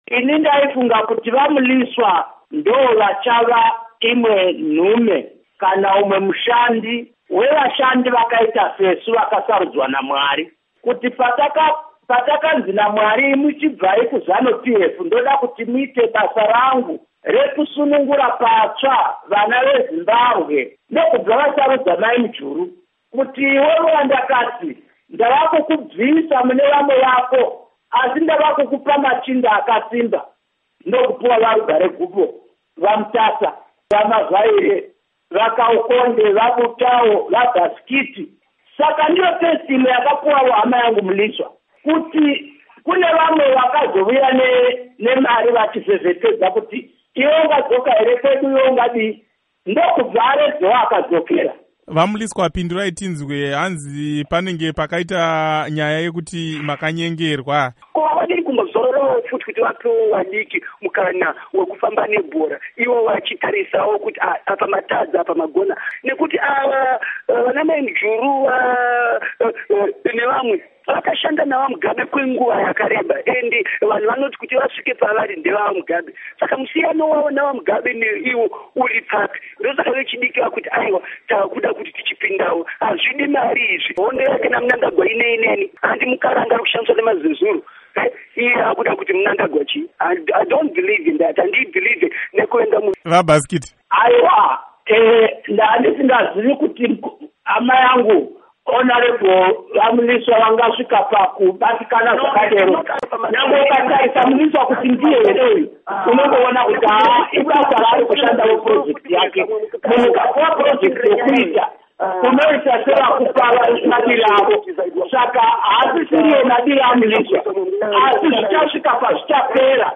Hurukuro naVaTemba Mliswa naVaKudakwashe Bhasikiti